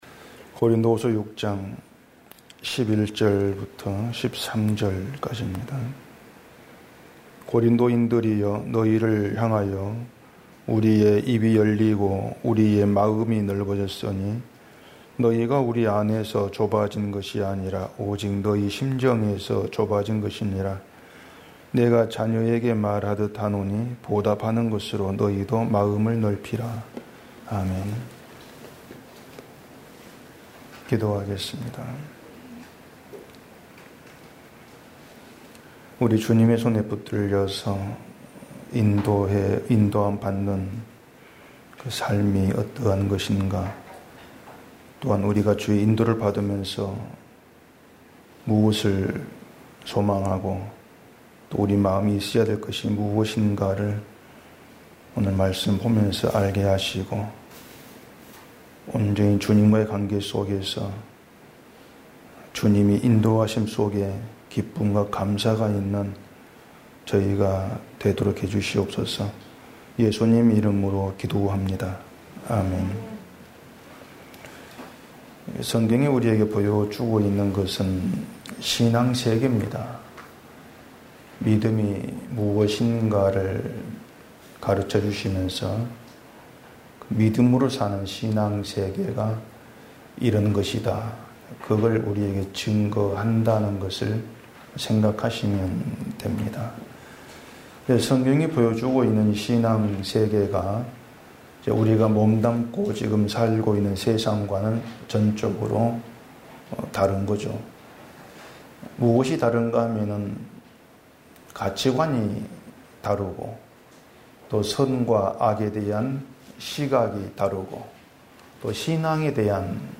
주일오전 - (40강) 마음을 넓히라